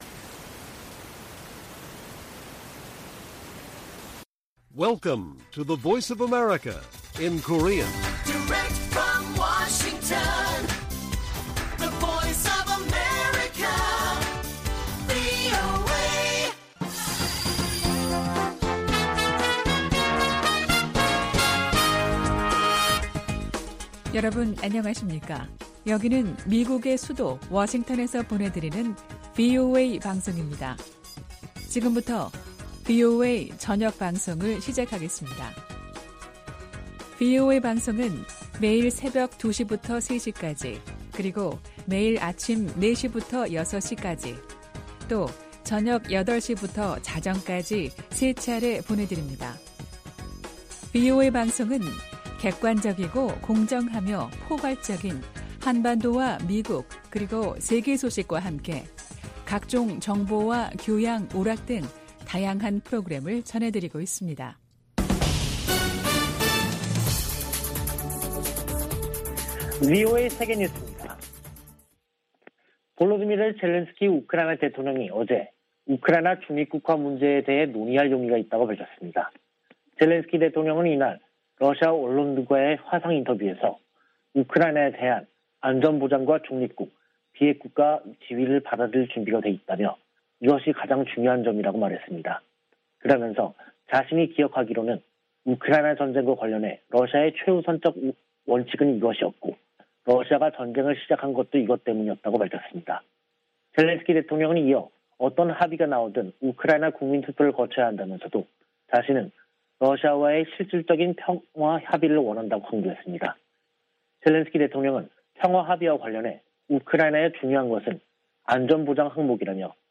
VOA 한국어 간판 뉴스 프로그램 '뉴스 투데이', 2022년 3월 28일 1부 방송입니다. 유엔 안보리가 북한 ICBM 발사에 대응한 공개 회의를 개최하고 규탄했으나 언론 성명 채택조차 무산됐습니다. 미 국무부는 중국과 러시아가 북한의 추가 도발 자제를 위해 강력한 메시지를 보내야 한다고 강조했습니다. 최근 ICBM 발사는 북한이 지난 몇 달간 보여준 도발 유형의 일부이며 추가 발사에 나설 것이라고 백악관 국가안보좌관이 전망했습니다.